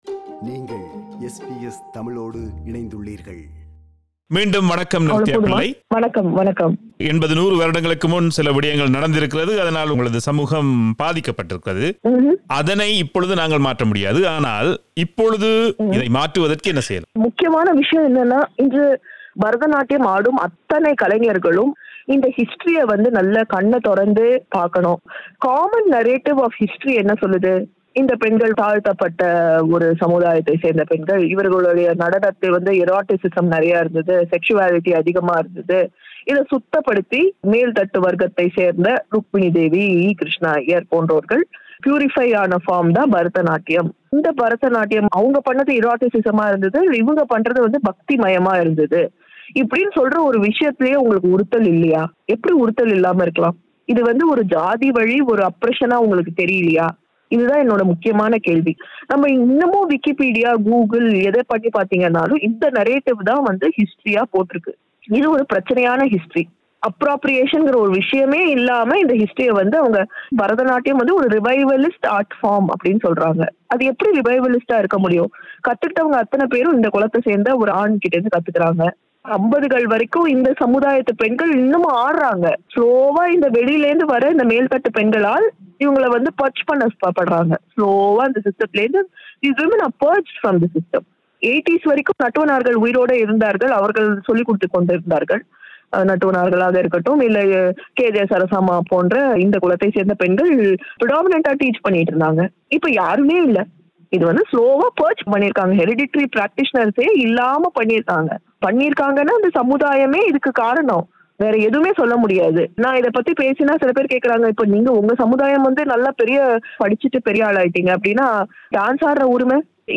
This is the final of the two-part interview.